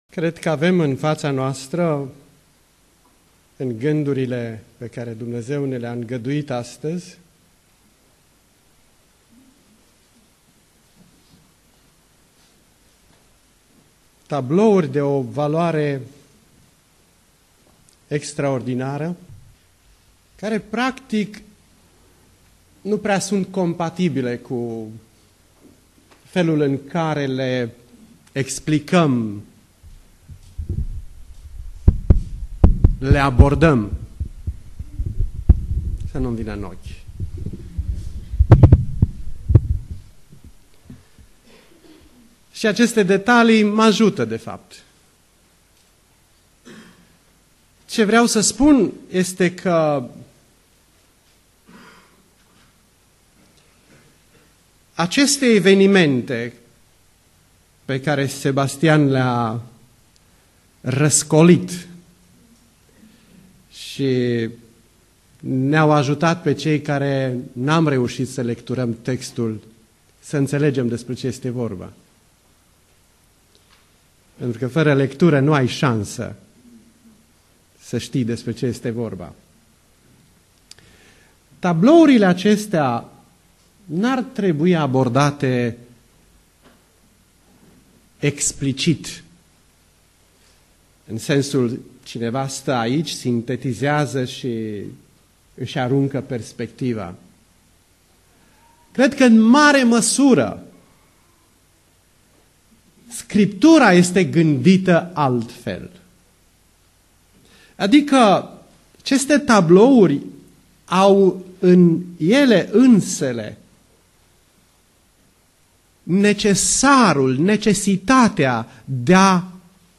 Predica Aplicatie 2 Imparati cap.6-8